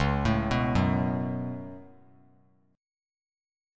Db7sus4#5 Chord
Listen to Db7sus4#5 strummed